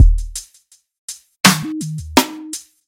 描述：汉诺威/德国的一种村庄绿地的短片。
Tag: towncentre 交通 craws 现场录音 双耳